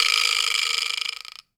pgs/Assets/Audio/Comedy_Cartoon/wood_vibraslap_hit_03.wav at master
wood_vibraslap_hit_03.wav